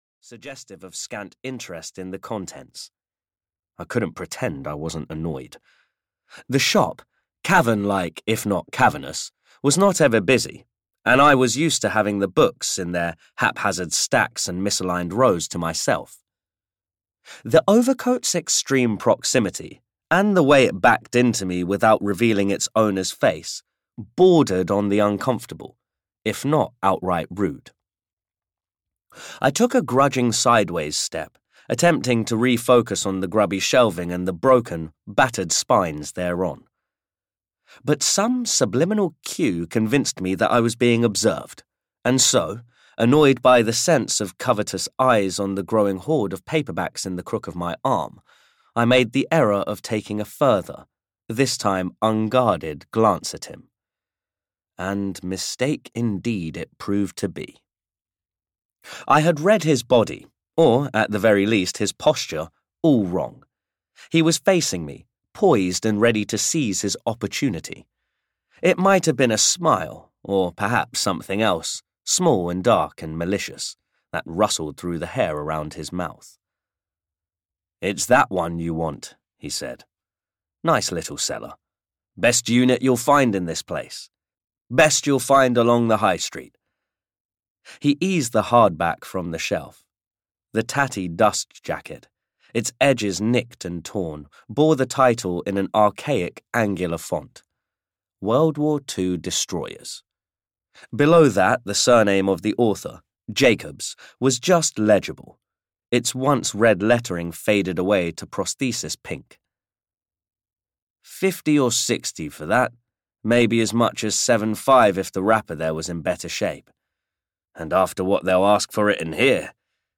The Pale Ones (EN) audiokniha
Ukázka z knihy